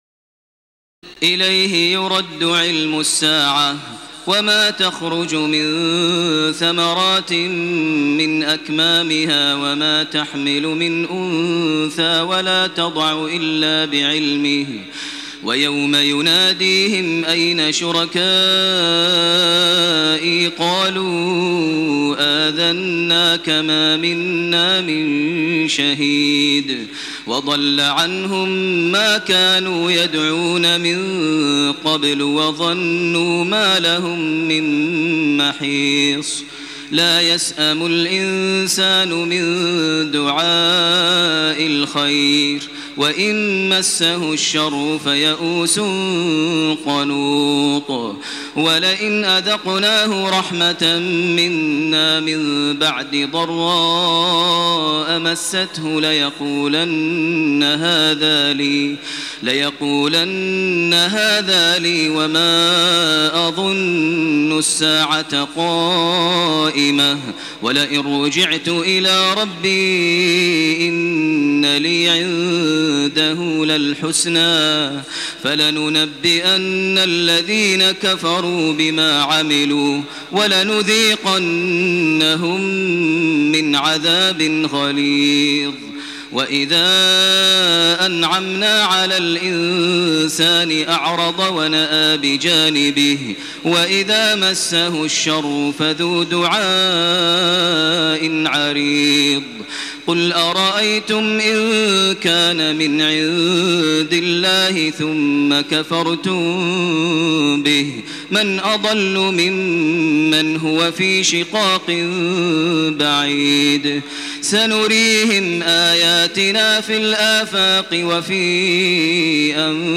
تراويح ليلة 24 رمضان 1428هـ من سور فصلت (47-54) و الشورى و الزخرف (1-25) Taraweeh 24 st night Ramadan 1428H from Surah Fussilat and Ash-Shura and Az-Zukhruf > تراويح الحرم المكي عام 1428 🕋 > التراويح - تلاوات الحرمين